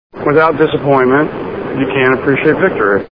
Gone in Sixty Seconds Movie Sound Bites
Gone in 60 Seconds Sound Bites